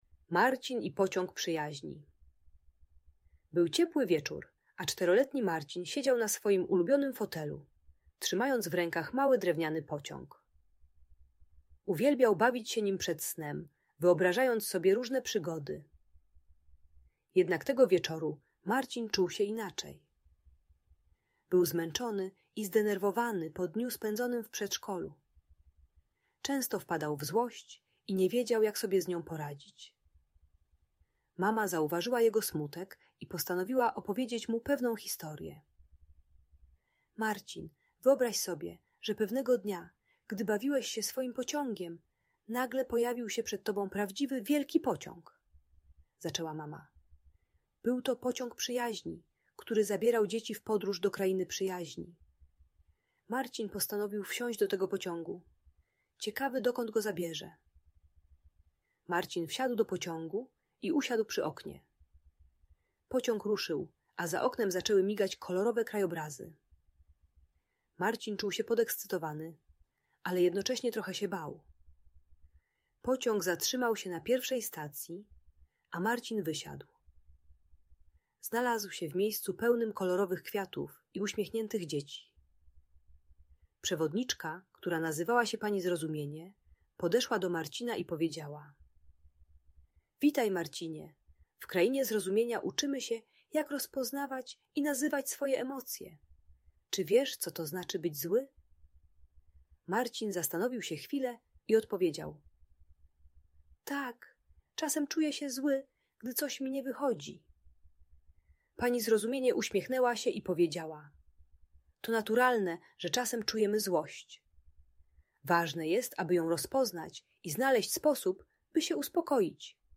Marcin i Pociąg Przyjaźni - Bunt i wybuchy złości | Audiobajka